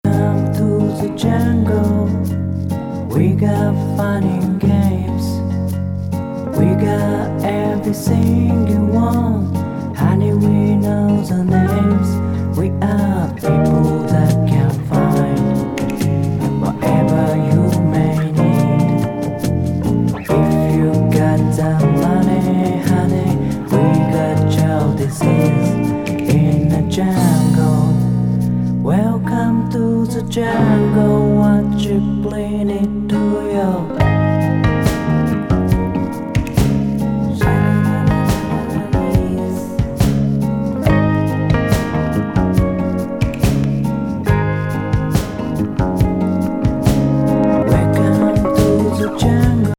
ダウンビート・ボサ・カバー。